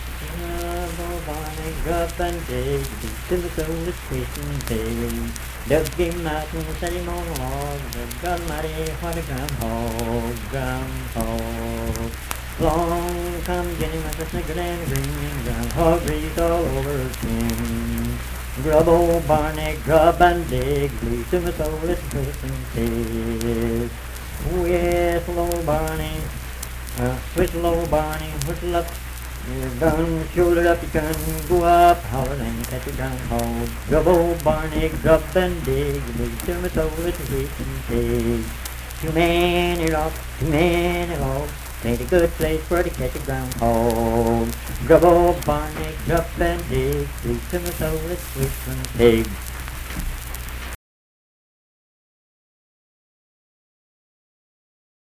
Unaccompanied vocal music
Verse-refrain 4(2)&R(2).
Performed in Dundon, Clay County, WV.
Dance, Game, and Party Songs
Voice (sung)